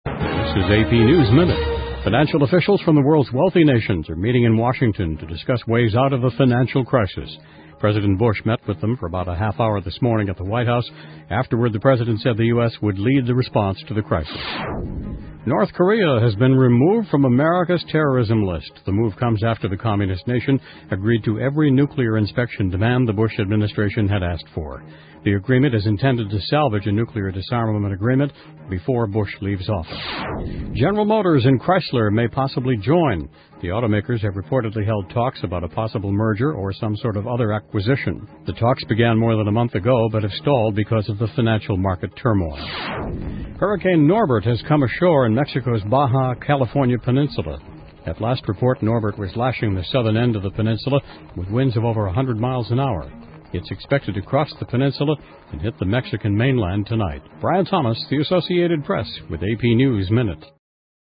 AP美联社一分钟新闻 2008-10-12 听力文件下载—在线英语听力室